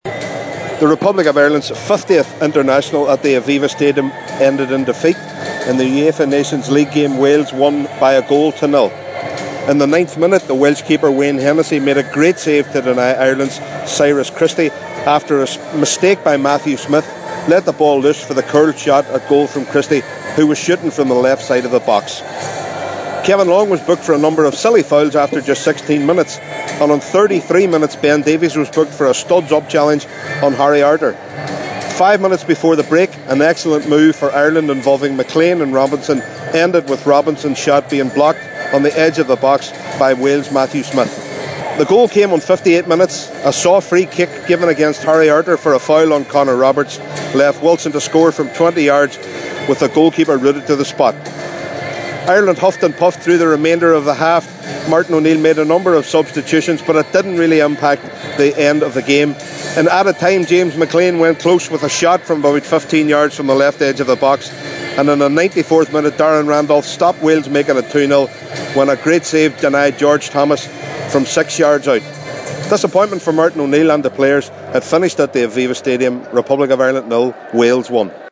Republic of Ireland 1- 0 Wales Match Report…